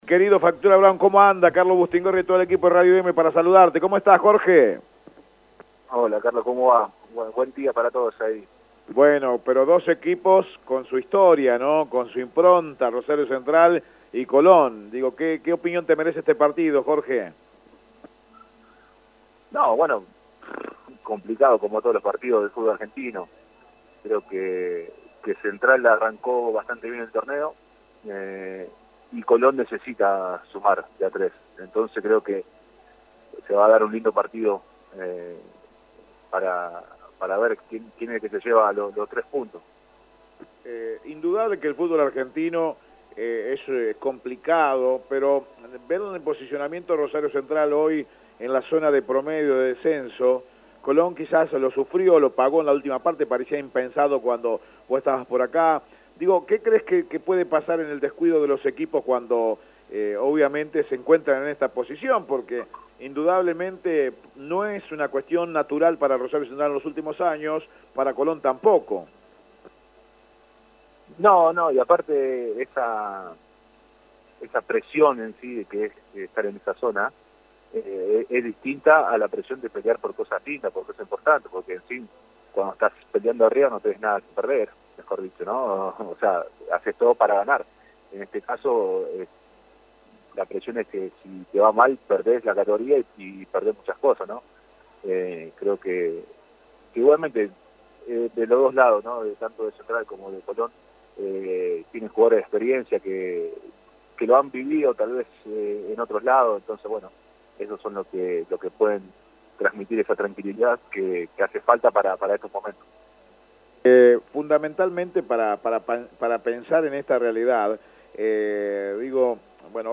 En la jornada de hoy, en la previa de Colón frente a Rosario Central, dialogamos con Jorge Broun, ex arquero del sabalero y el canalla, actual jugador del Ludogorets Razgrad de la Liga A de Bulgaria. “Fatura” recordó su paso por el fútbol santafesino. Además, habló acerca de la actualidad.